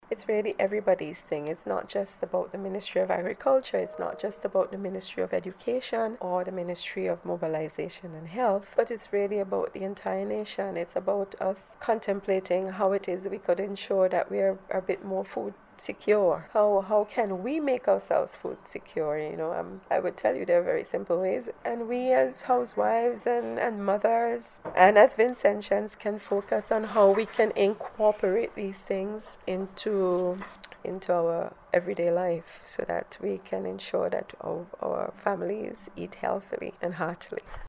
Speaking in an interview with NBC News